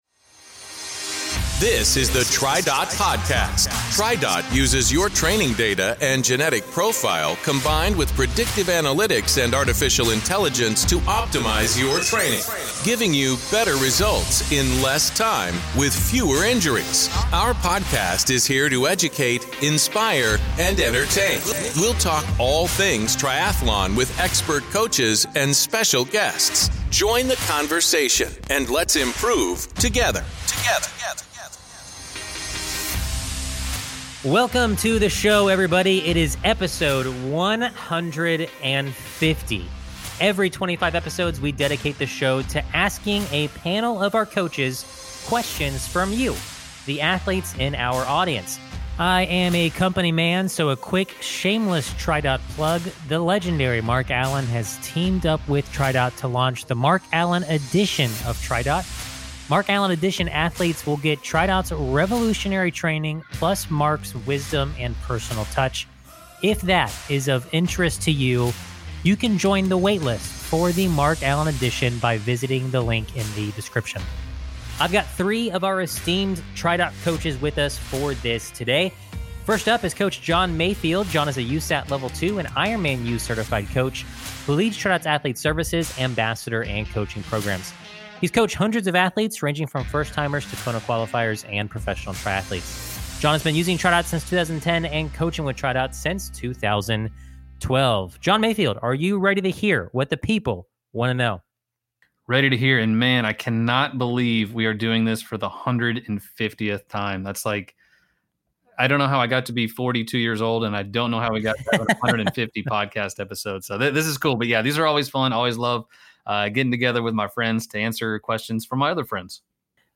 Learn how to best plan your season for a peak performance, shorten your transition times, and combat post-race nausea. Listen in as the coaches talk about returning to training after illness, implementing a run/walk strategy for your next race, recommended swim drills and stroke rate, and much more!